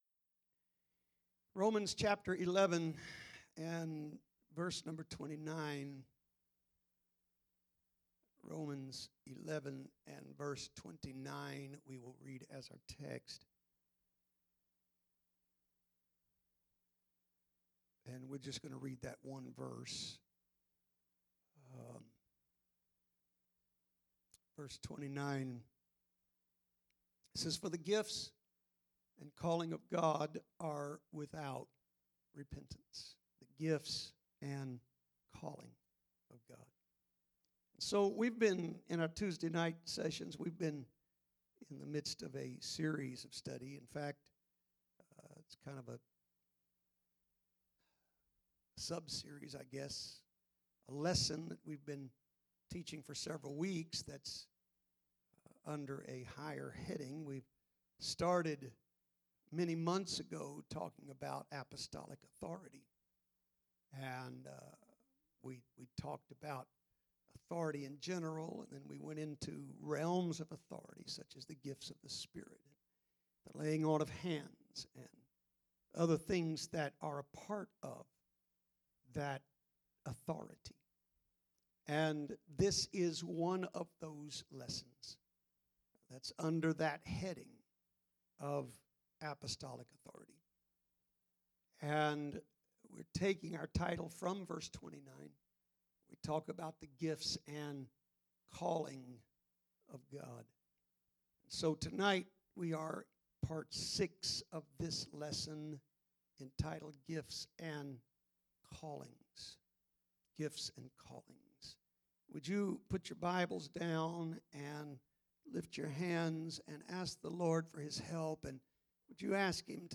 Gifts and Callings Service Type: Tuesday Evening « First of All